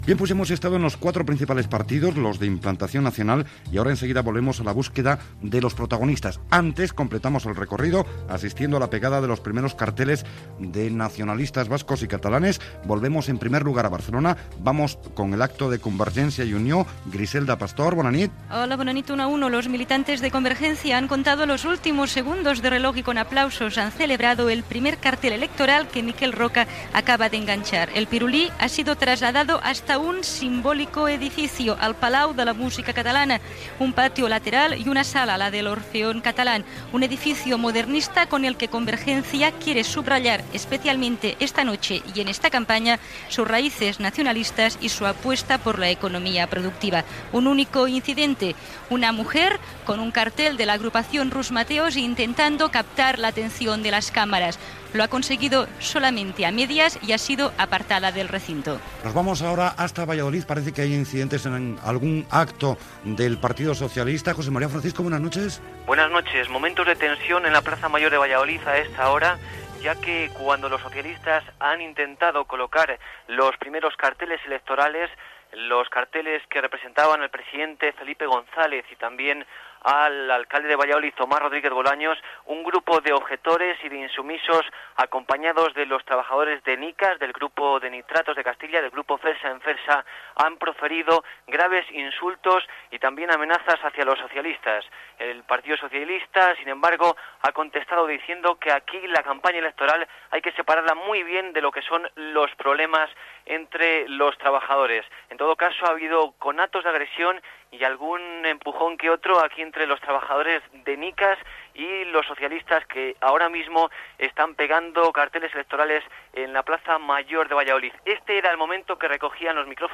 Informatiu especial "La campaña en hora 25" la nit de l'inici de la campanya dels partits polítics que es presentaven a les eleccions generals espanyoles.
Connexió amb el Palau de la Música de Barcelona, on Convergència i Unió comneça la campanya electoral, i amb Valladolid. Entrevista informativa telefònica al candidat Julio Anguita d'Izquierda Unida. Previsió del temps i nombre d'actes polítics previstos
Informatiu